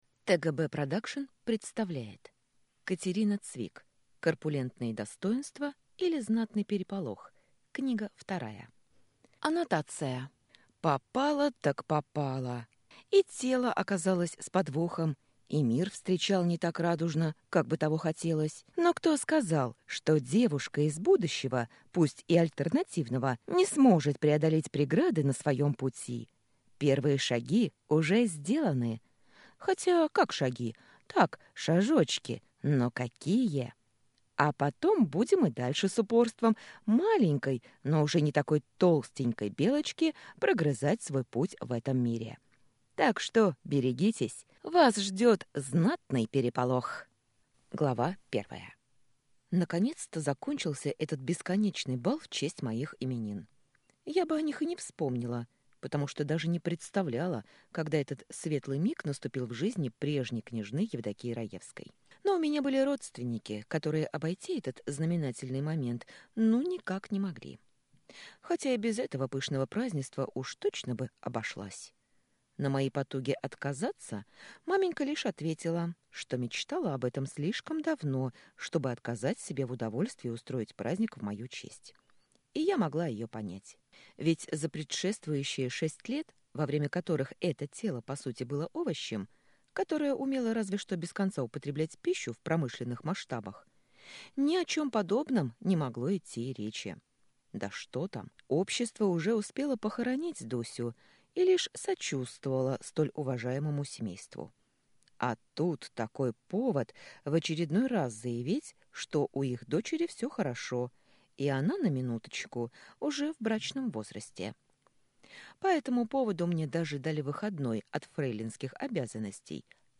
Аудиокнига Корпулентные достоинства, или Знатный переполох 2 | Библиотека аудиокниг